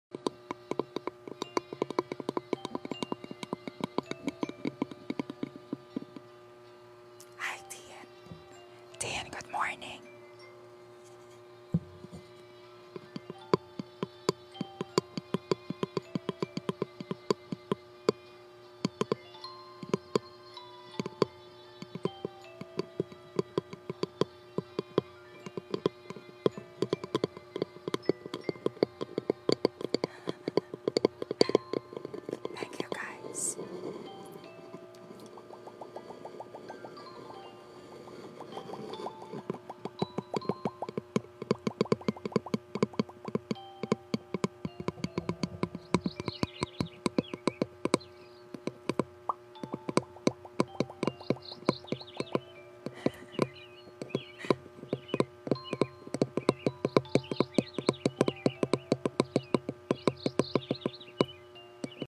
ASMR WOOD TAPPING, MOUTH SOUNDS Sound Effects Free Download
ASMR WOOD TAPPING, MOUTH SOUNDS & WHISPERING